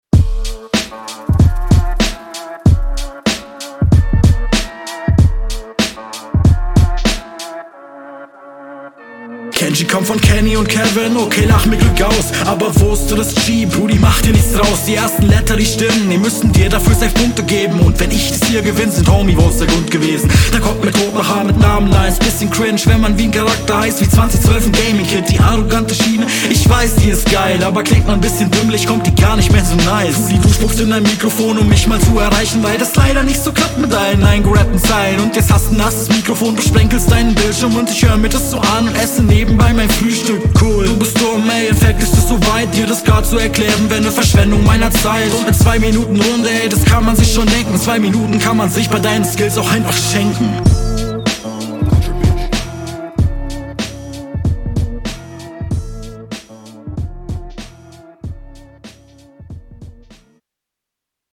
n bissl viel delay, doubles auch leicht off, aber sonst sehr cool, konter vorteil sehr …